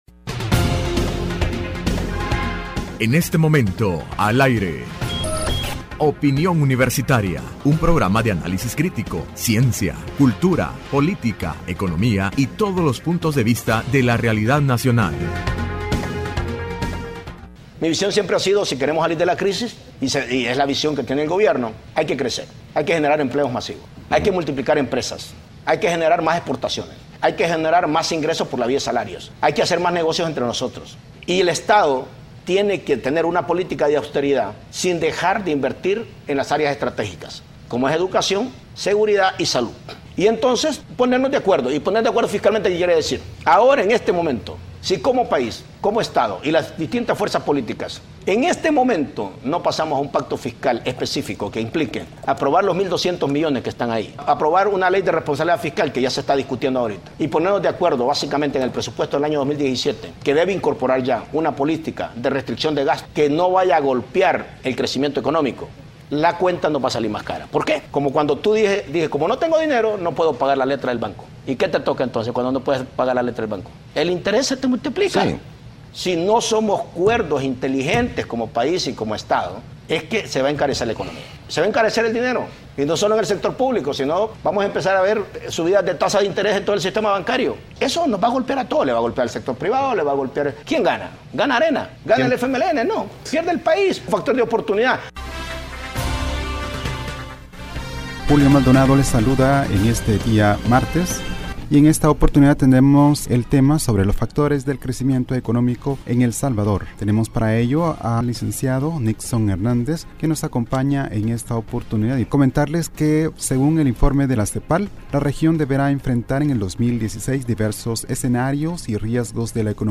Entrevista Opinión Universitaria (23 agosto 2016) : Análisis del crecimiento económico de El Salvador.